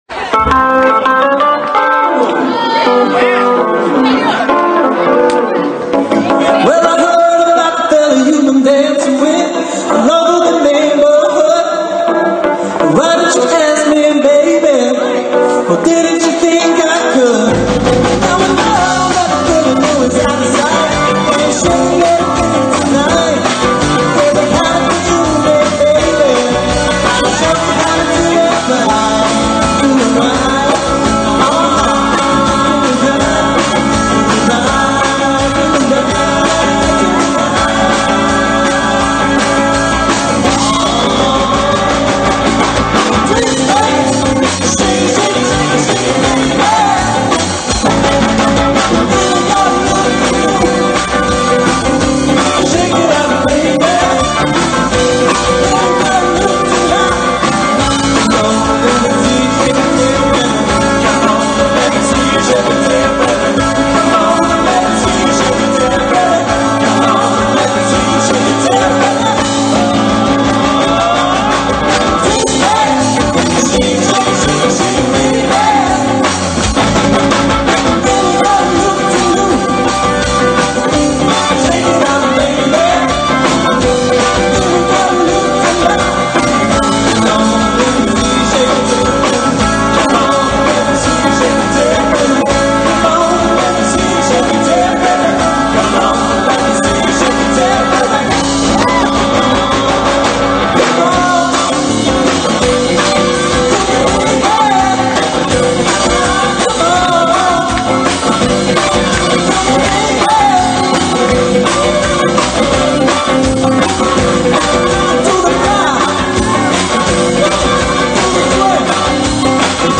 high energy 4 piece rock band
Lead Guitar
Drums
Bass
Vocals